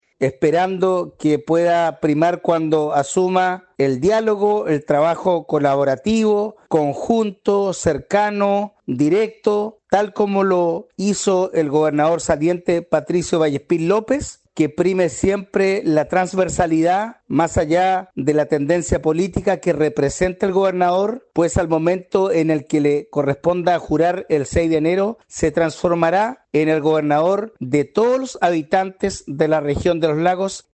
El presidente de la Asociación de Municipios de Chiloé y alcalde de Queilen, Marcos Vargas, expresó que esperan que prime el diálogo y el trabajo colaborativo.